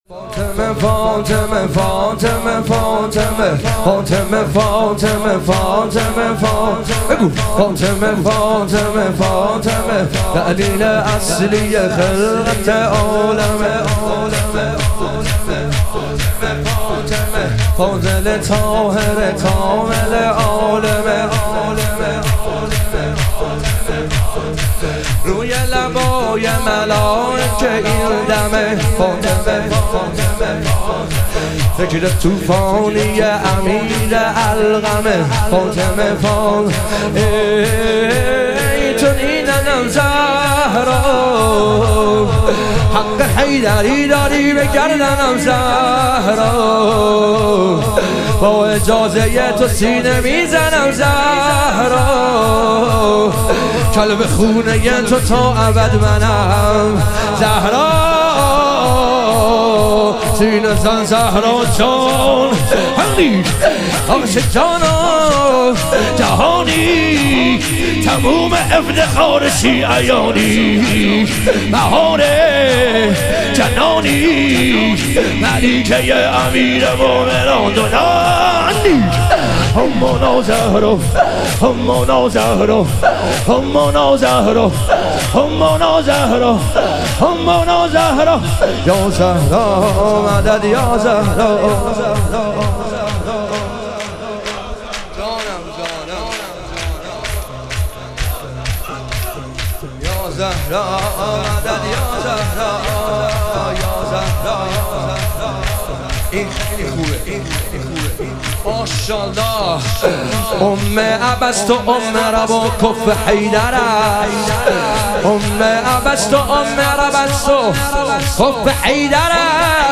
ایام فاطمیه دوم - شور